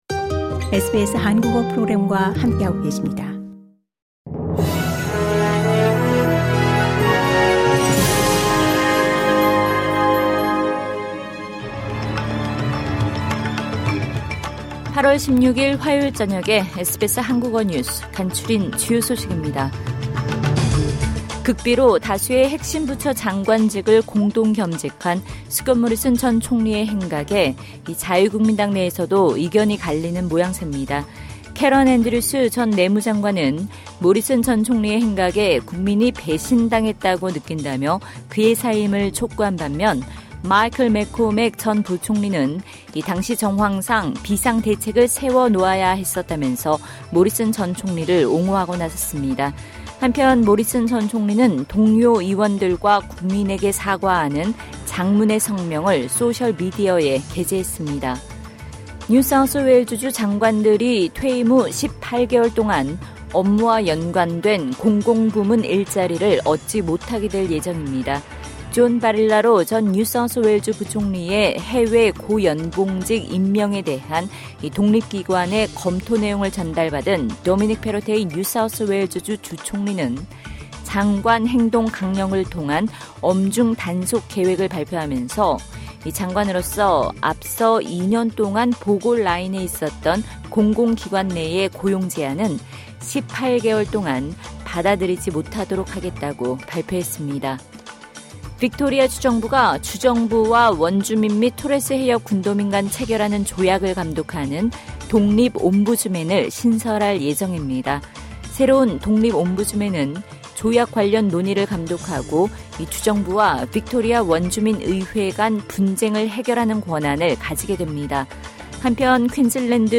SBS 한국어 저녁 뉴스: 2022년 8월 16일 화요일